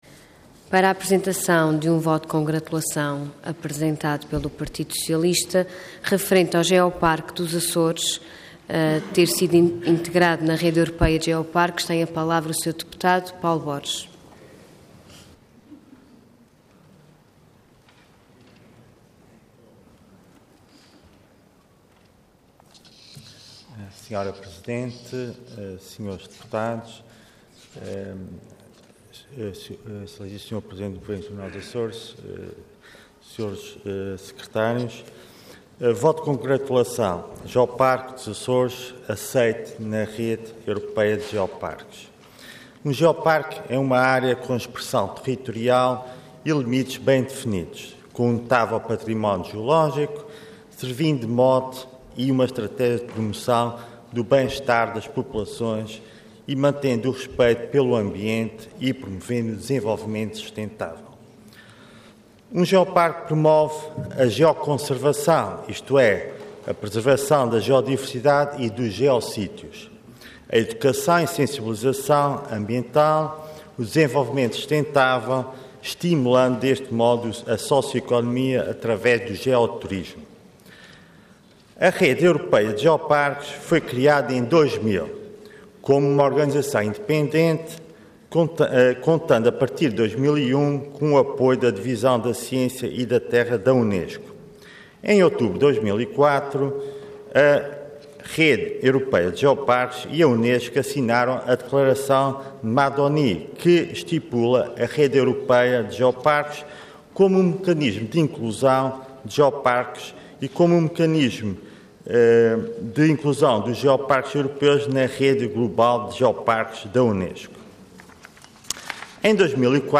Website da Assembleia Legislativa da Região Autónoma dos Açores
Intervenção Voto de Congratulação Orador Paulo Borges Cargo Deputado Entidade PS